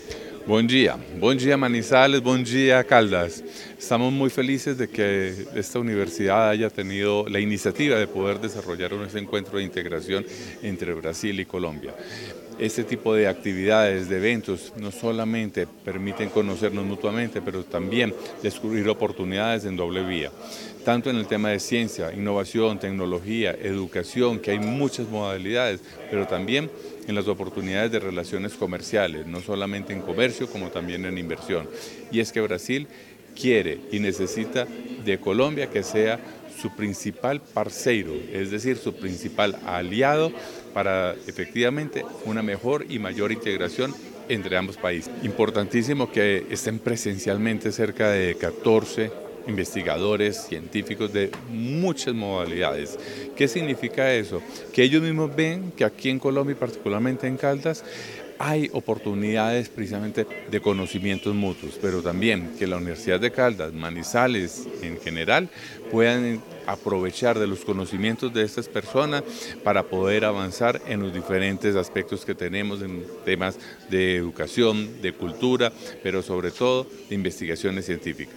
En el acto de apertura, el Cónsul honorario de Brasil, Sergio Escobar, se mostró complacido de haber aceptado la invitación de la Universidad de Caldas para propiciar este encuentro de cooperación.
-Audio Cónsul honorario de Brasil, Sergio Escobar.